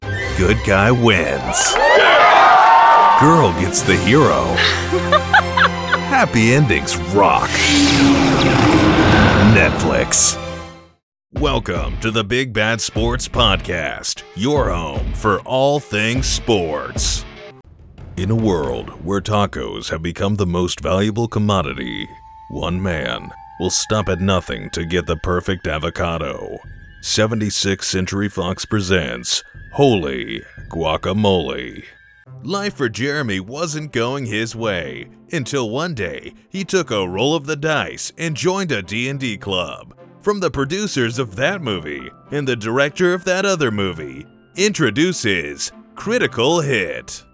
Promo Sample